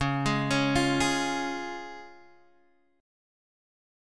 Db chord